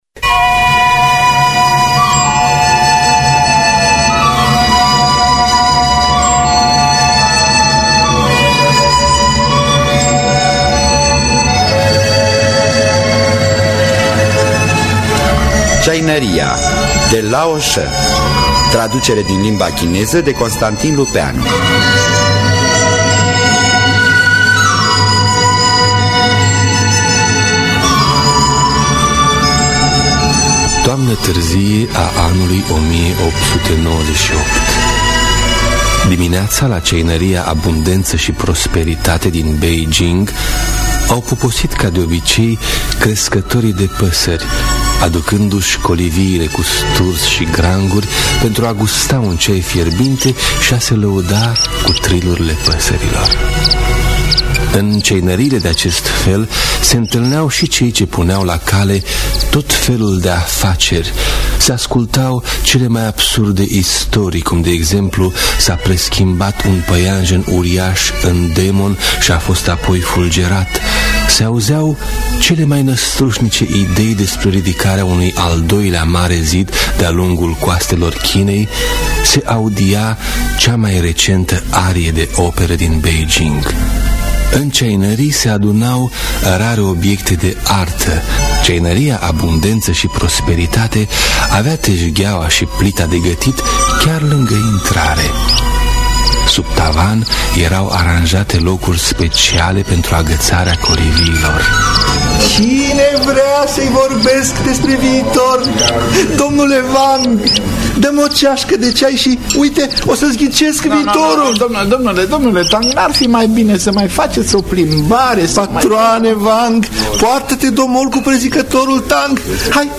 Adaptarea radiofonic
Înregistrare din anul1981.